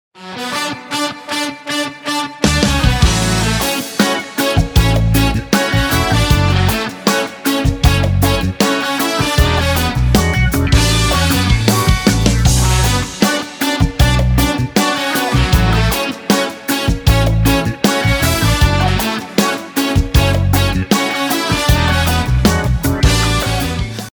• Качество: 320, Stereo
громкие
без слов
Cover
инструментальные
Саксофон